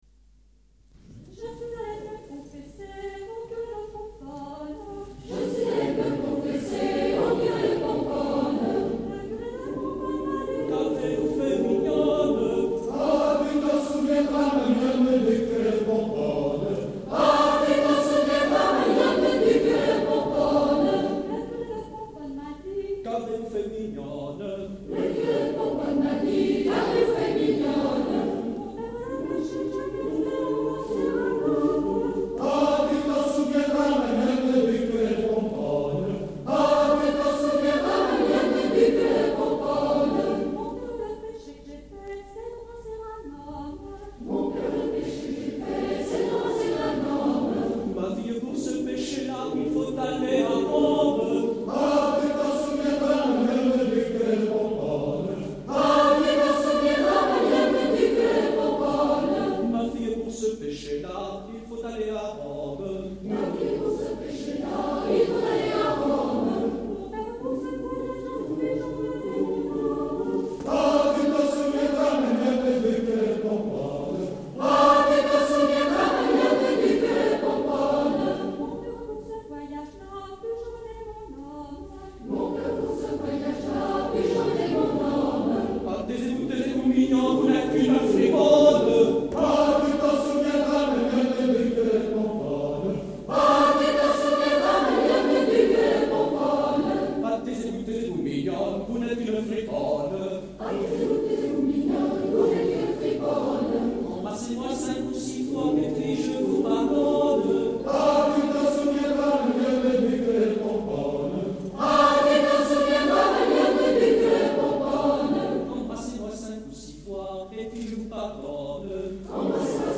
Concert "Ménétris"&"Saltarelle" dimanche 11 février 1979 Eglise du Bourg CHARNAY-LES-MACON
Extaits du Concert de Charnay